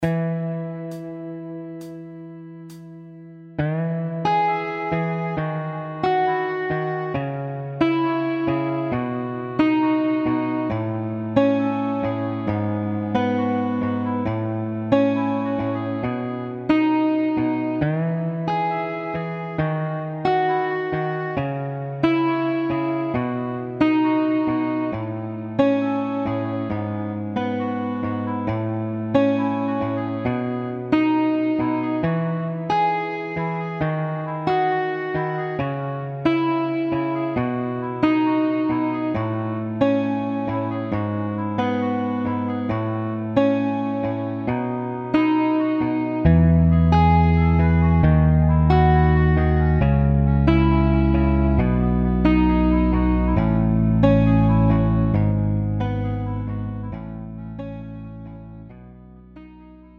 음정 -1키
장르 pop 구분 Pro MR